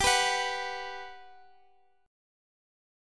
B5/G chord